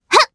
Frey-Vox_Jump_jp.wav